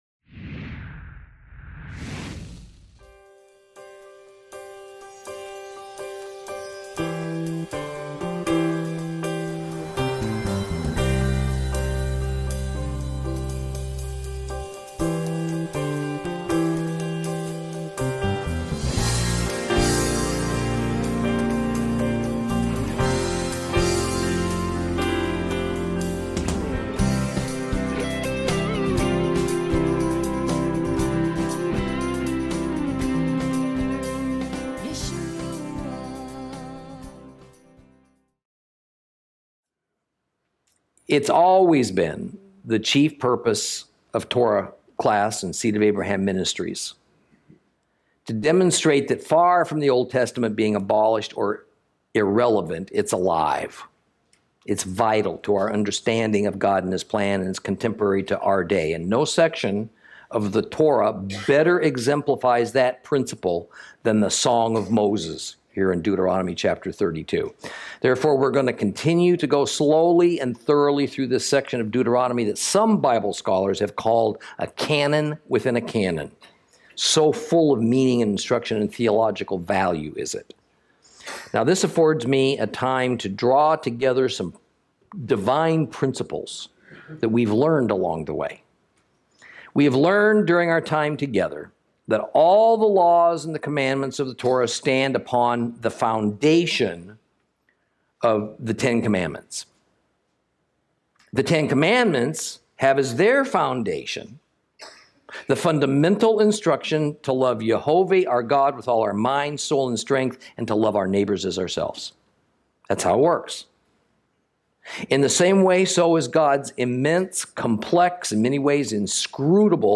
Lesson 46 Ch32 - Torah Class